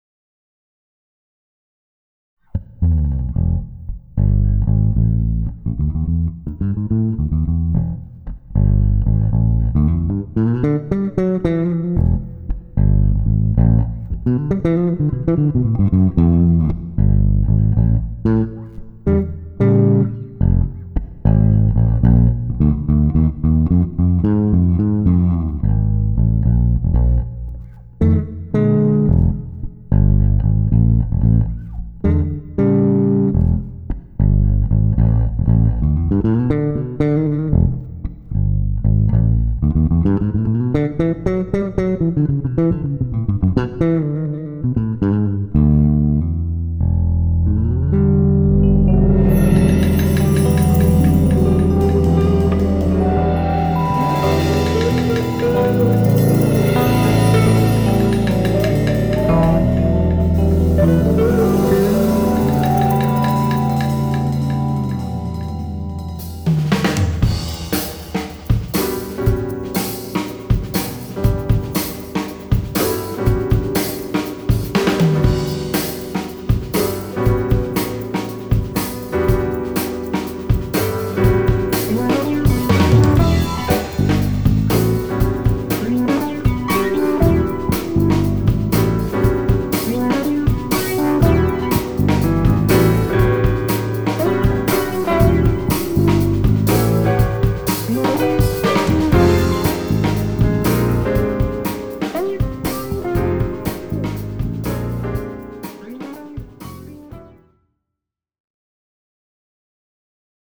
Martens 4 snarige bas